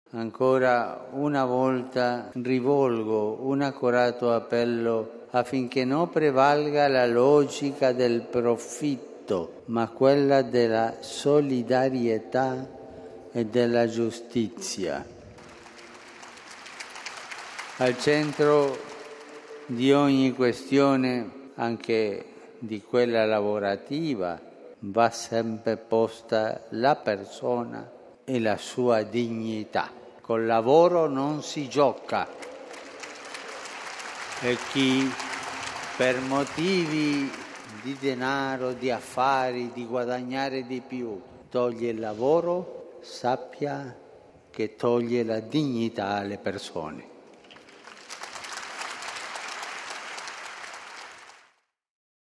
Al termine dell’udienza generale, salutando i fedeli italiani, il Papa è tornato a parlare della difficile situazione degli operai della Thyssenkrupp, l’acciaieria tedesca che sta portando avanti un drastico piano di tagli al personale.